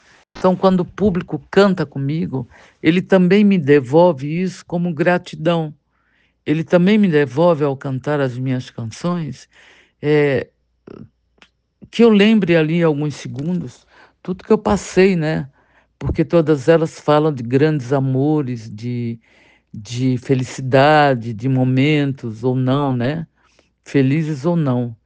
“Não abro mão da poesia, da história, boa música e da arte” diz Roberta Miranda em entrevista exclusiva para o Curitiba Cult; cantora faz show na cidade em agosto